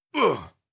RiftMayhem / Assets / 1-Packs / Audio / NPC or Player / Damage Sounds / 02.
02. Damage Grunt (Male).wav